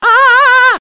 One of Mario's voice clips in Mario & Luigi: Superstar Saga
MarioScreamingM&LSS.mp3